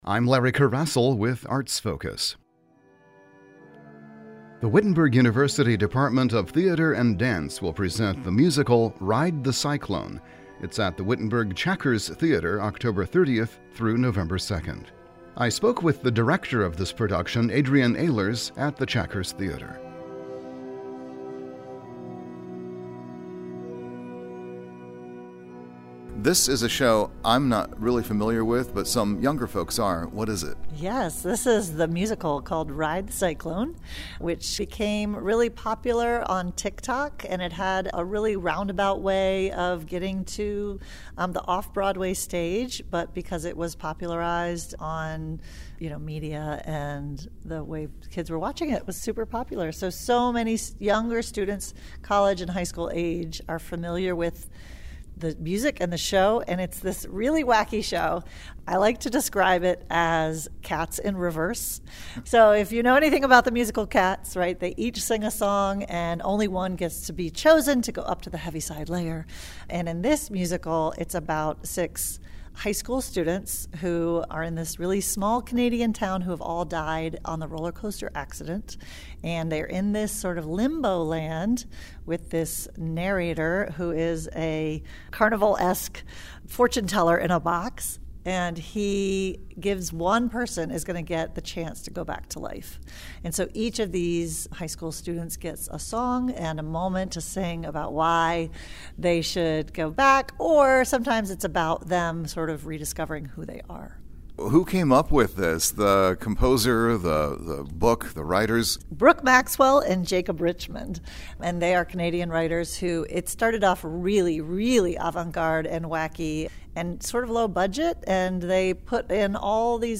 Each segment features a guest from the regional arts community discussing current activities, such as concerts, exhibitions and festivals.